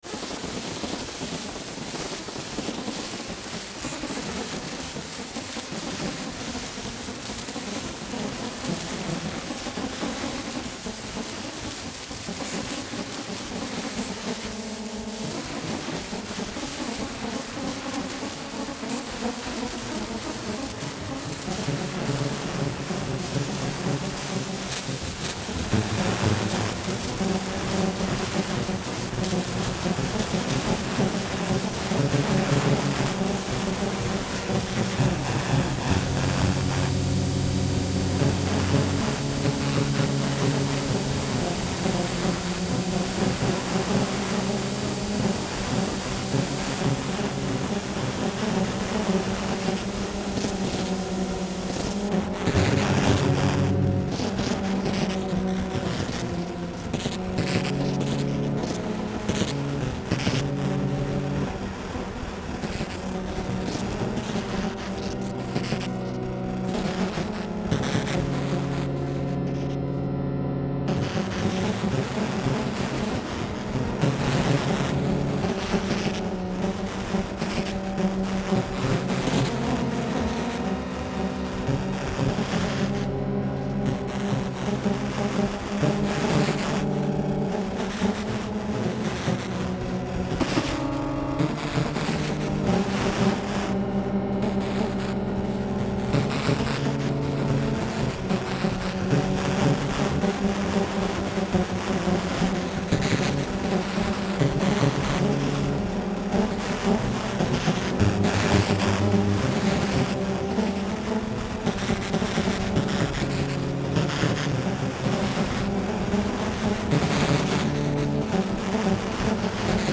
Rhythmical_Insects.mp3